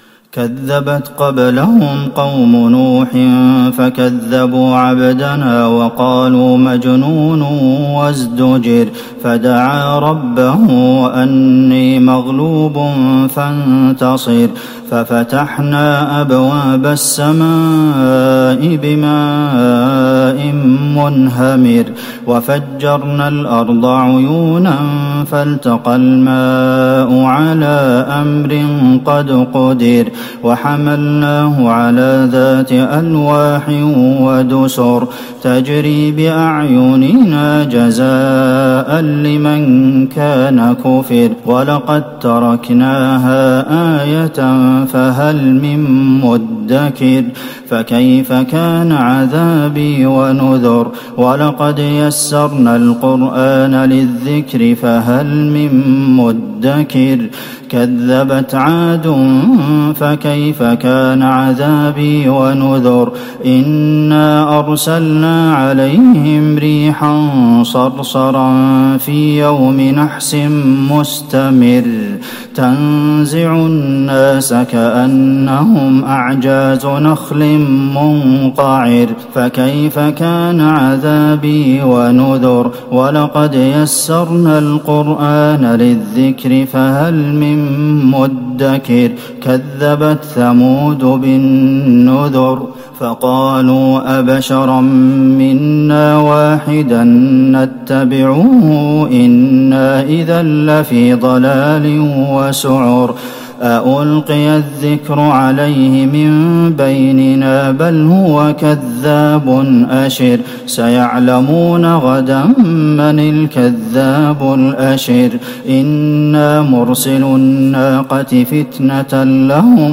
تراويح ٢٨ رمضان ١٤٤١هـ من سورة القمر ٩- النهاية والرحمن والواقعة كاملتين > تراويح الحرم النبوي عام 1441 🕌 > التراويح - تلاوات الحرمين